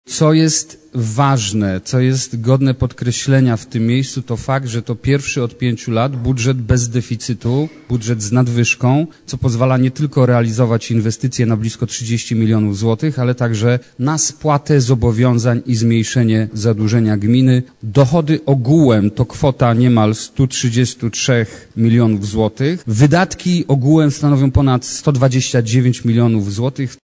Podczas ostatniej sesji Rady Miejskiej w Myśliborzu odbyła się debata między radnymi dotycząca przychodów i wydatków gminy w przyszłym roku.
Główne elementy składowe budżetu przedstawił Burmistrz Piotr Sobolewski